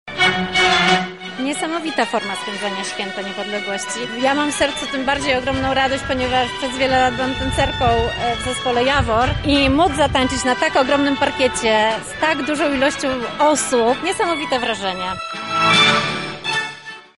tancerka